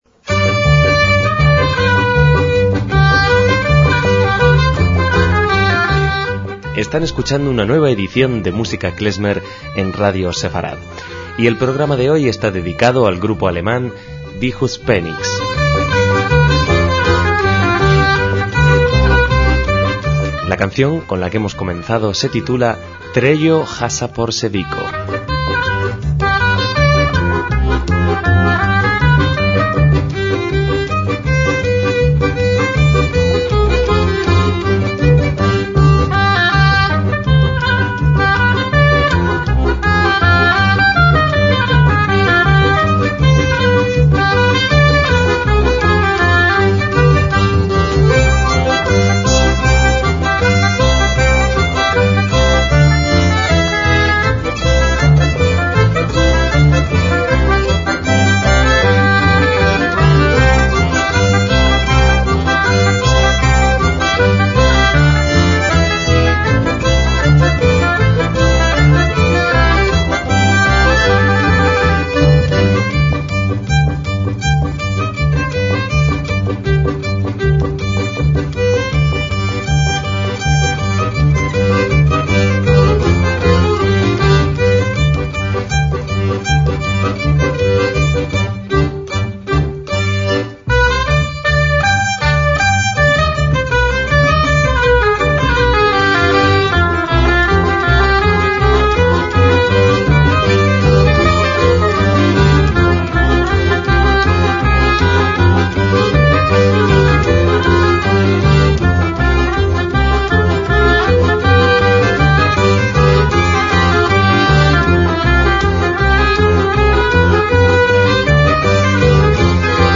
MÚSICA KLEZMER
klezmer y las canciones en ídish
voz
violín
oboe y corno inglés
acordeón
contrabajo